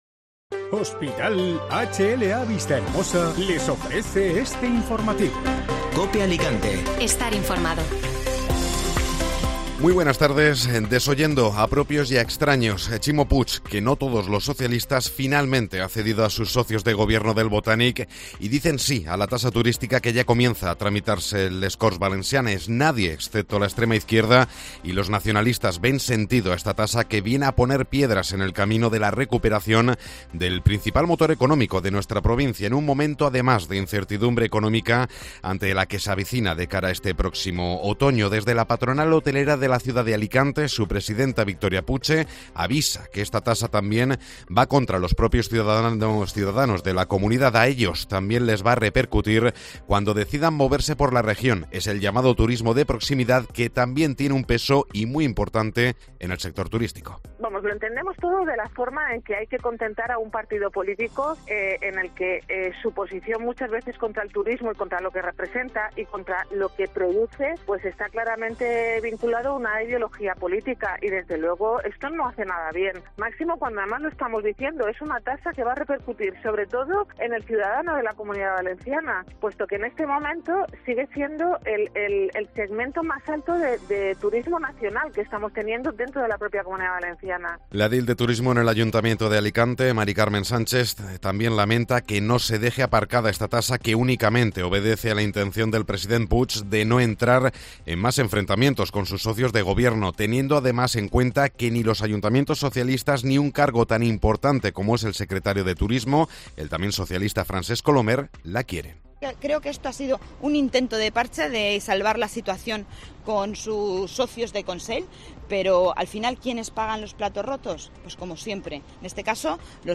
Informativo Mediodía Cope (Viernes 15 de Julio)